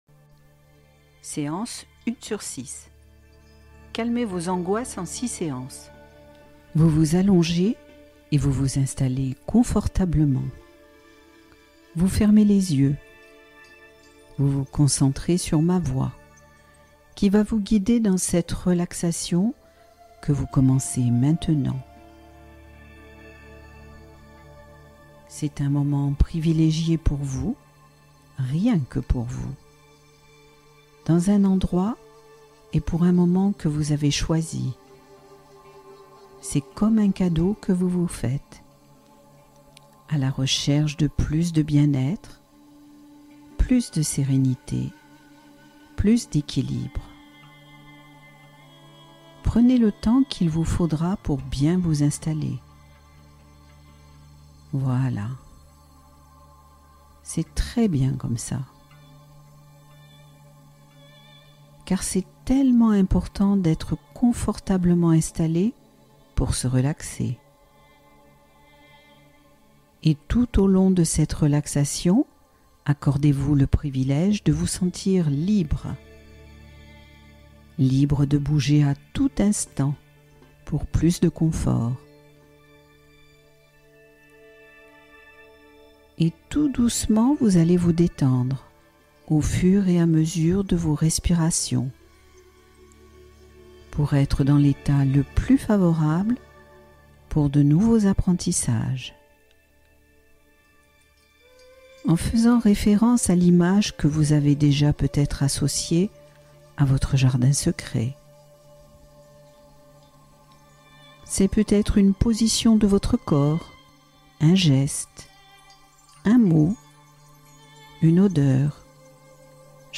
Relaxation guidée : sommeil réconfortant et profond